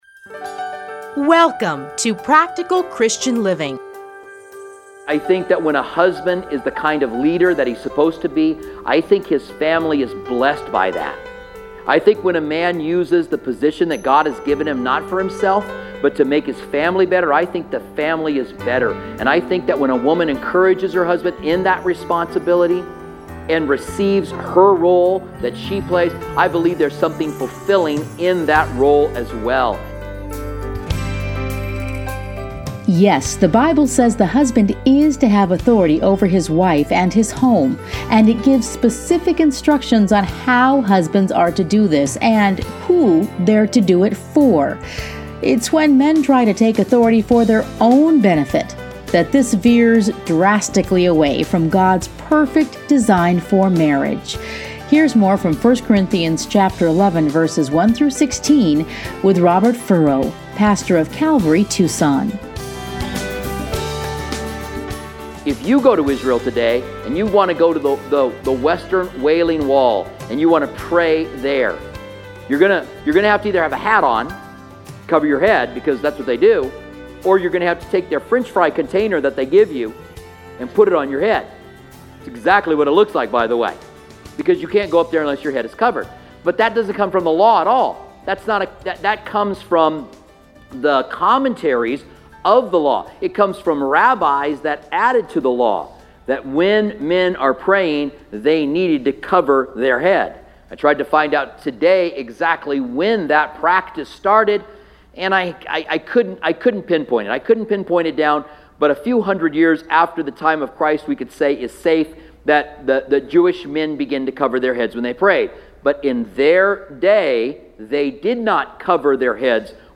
Listen here to a teaching from 1 Corinthians.